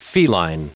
Prononciation du mot feline en anglais (fichier audio)
Prononciation du mot : feline